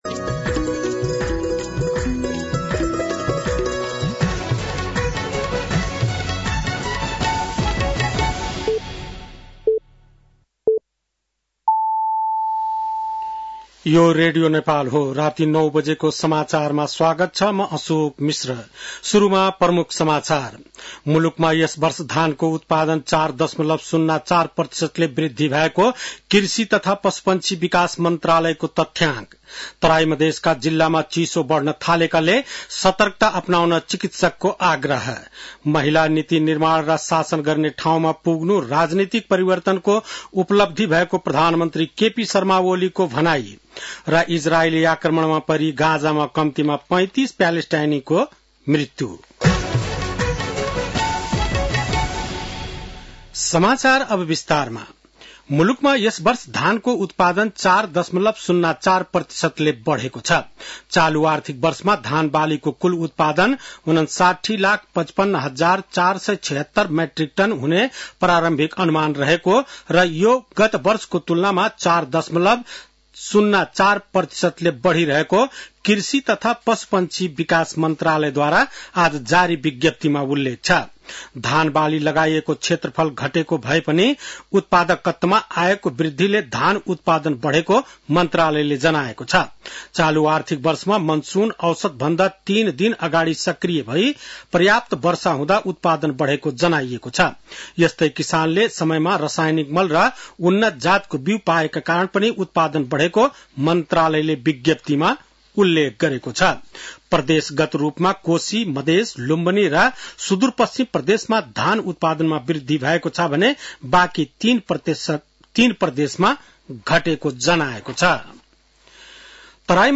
बेलुकी ९ बजेको नेपाली समाचार : २० पुष , २०८१
9-PM-Nepali-News-9-19.mp3